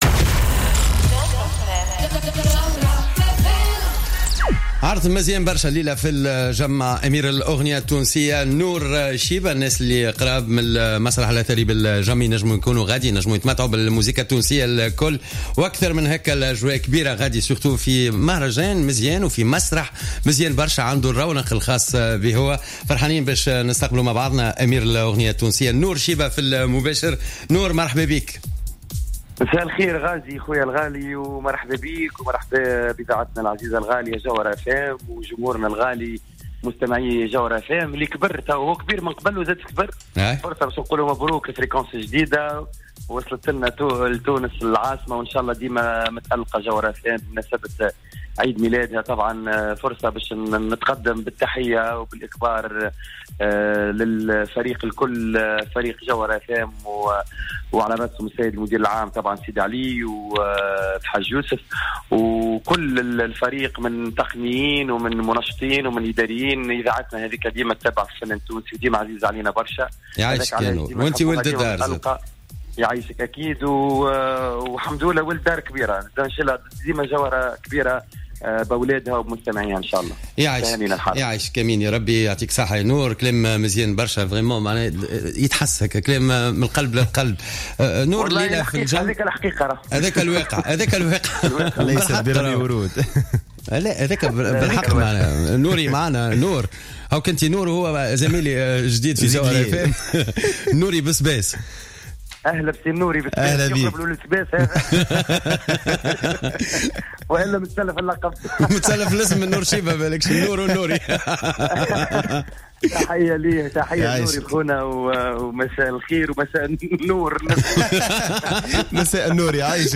وأضاف شيبة خلال مداخلته في برنامج "jawhara by night"، أن عروضا أخرى مبرمجة سيحييها في مكنين وجمال وجرجيس وجبنيانة، وغيرها من الولايات والأماكن التونسية.